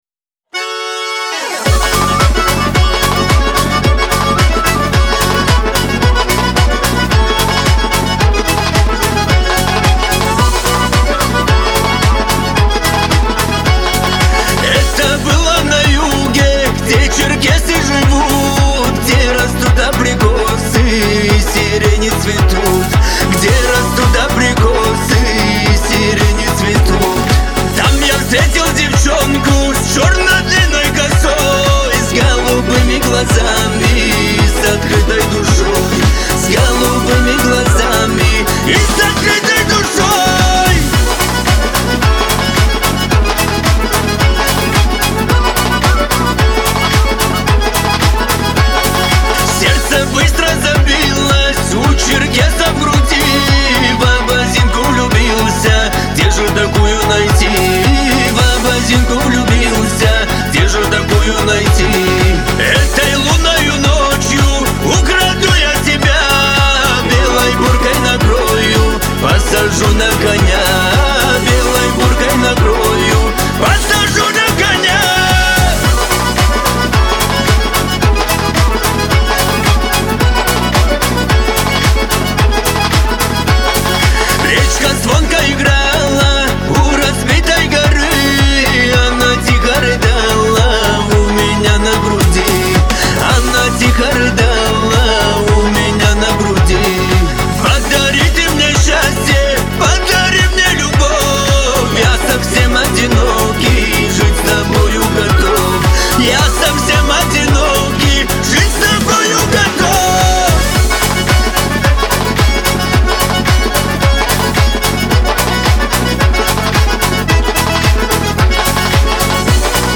это яркая и мелодичная песня в жанре поп
его голос наполнен теплотой и энергией.